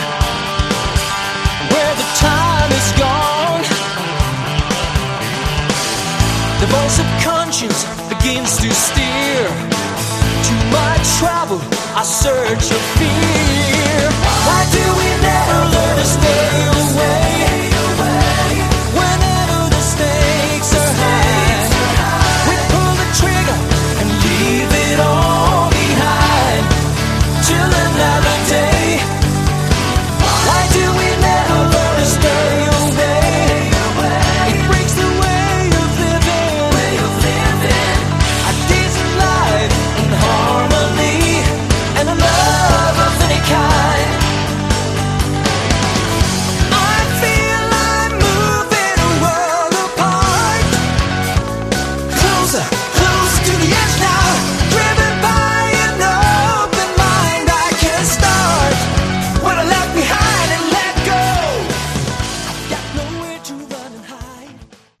Category: AOR
lead and backing vocals, electric and acoustic guitars
drums
bass
keyboards